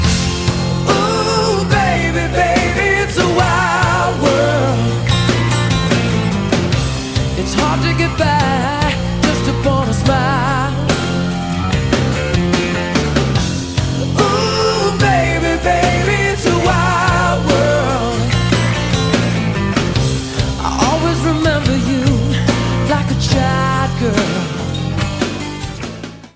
Категория: Rock